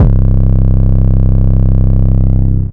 808 6 {C} [ plugg ].wav